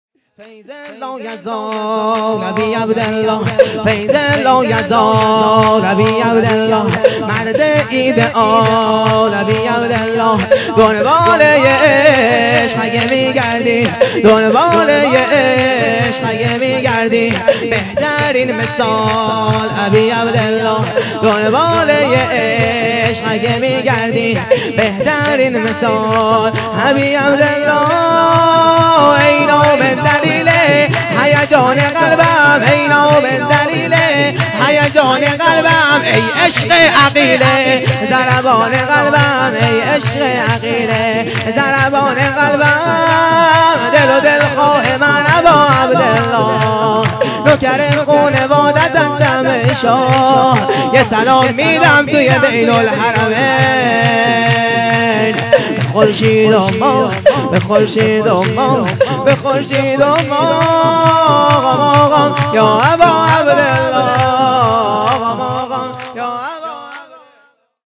ویژه برنامه جشن بزرگ اعیاد شعبانیه و میلاد انوار کربلا1403